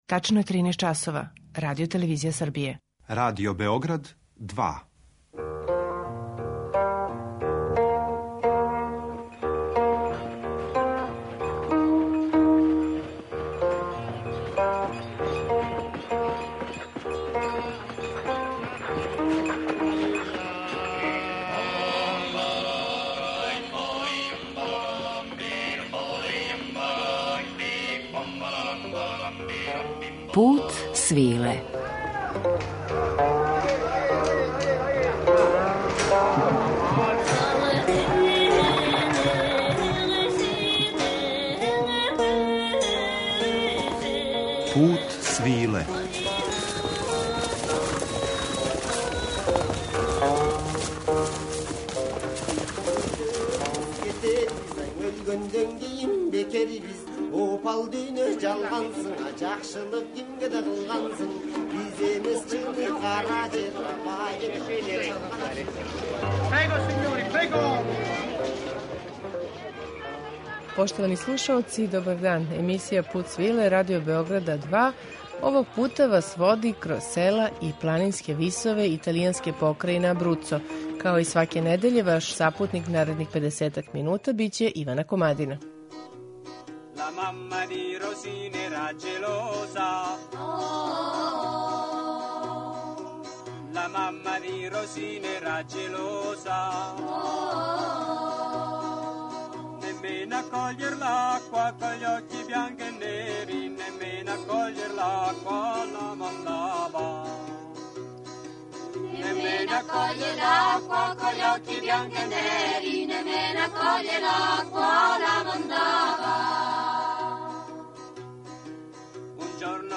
Данашњи Пут свиле води кроз села и планинске висове Абруца током летње трансуманце, у друштву сеоских музичара који су некада градили звучни амбијент ове италијанске покрајине. Салтареле, спалате, полке, мазурке, шоте, баладе и шаљиве песме из Абруца
на архивским снимцима